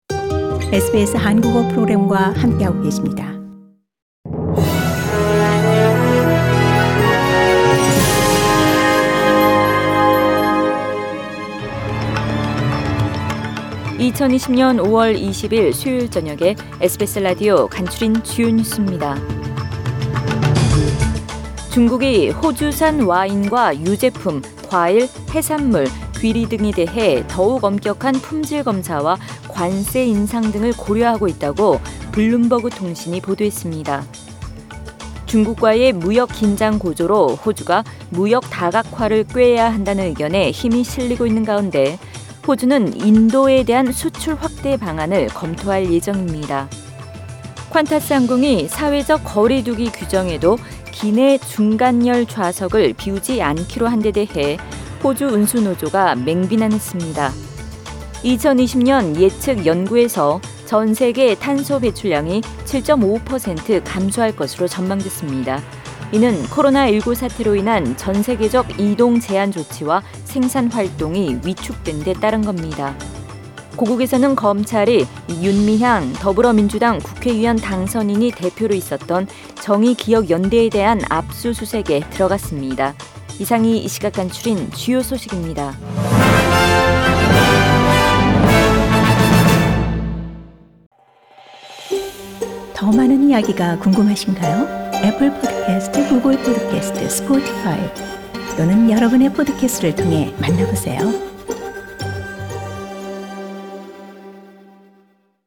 2020년 5월 20일 수요일 저녁의 SBS Radio 한국어 뉴스 간추린 주요 소식을 팟 캐스트를 통해 접하시기 바랍니다.